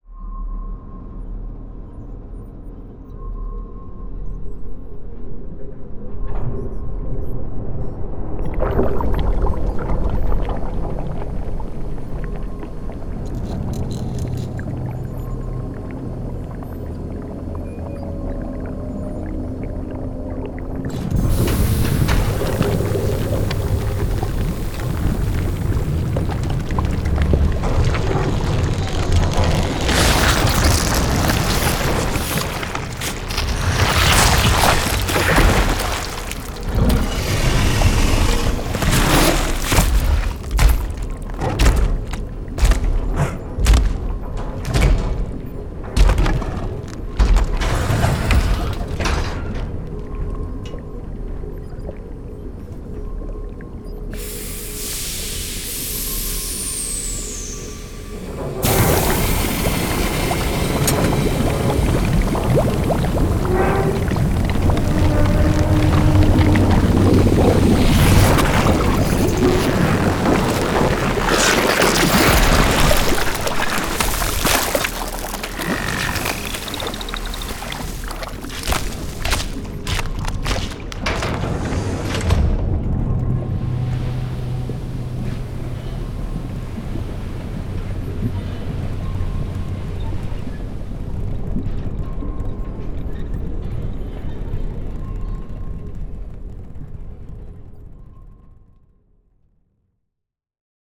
Pokémon Clone Labratory [Scifi/slime ambience]
The scene starts calm and quiet. But as the lab hums into life, we hear the sounds of tanks bubbling as they power on. The bubbling becomes a frothing churning as a new Pokemon decends through the massive green tubes before squelching through the strange, liquid metal walls of the final chambers. The Pokemon picks itself up and begins walking towards a large metal door, making space for the next to arrive...